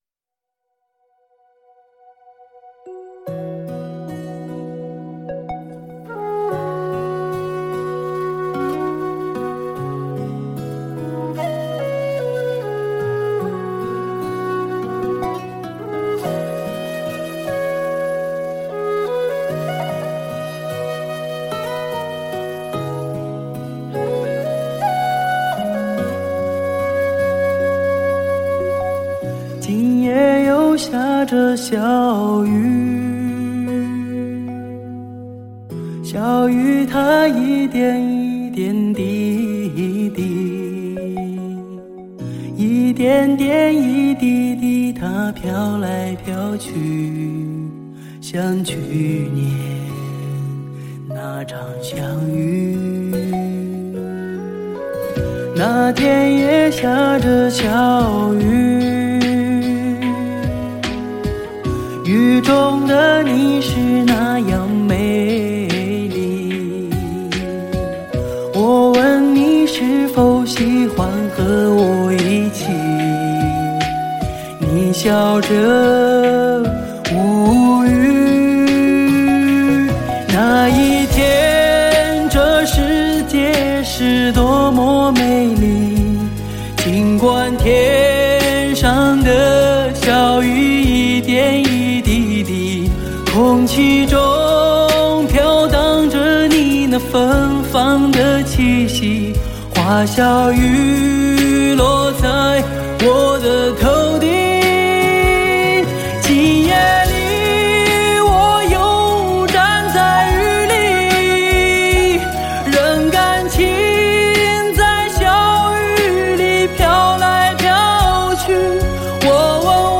Ps：在线试听为压缩音质节选，